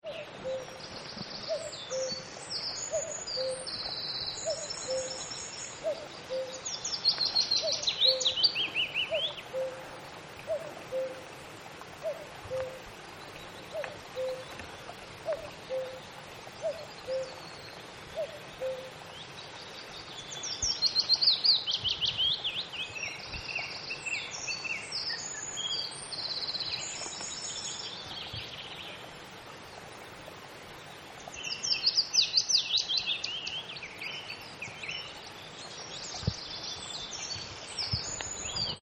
cuckoo & other birds